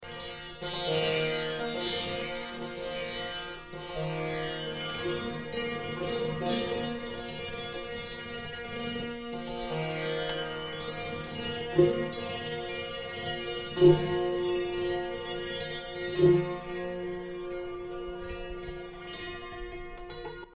SaitenfeldTambura.mp3